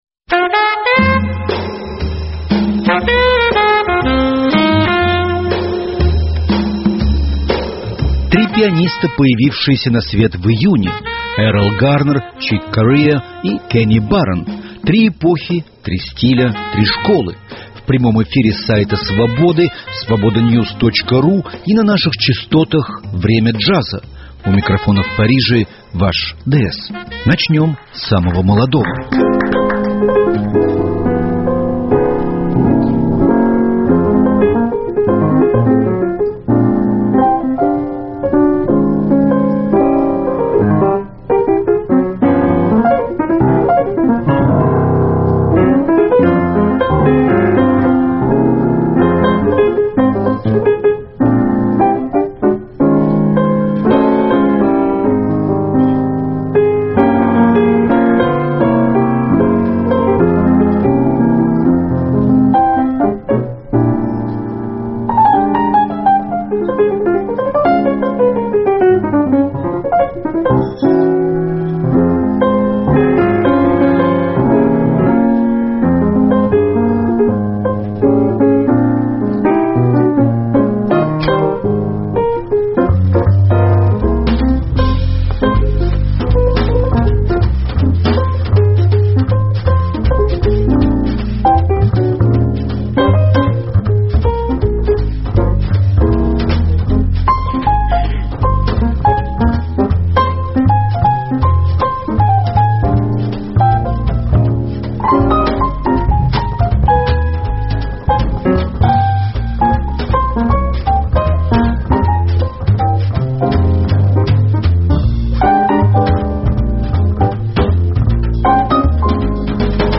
Восемь пианистических пьес и – один вокал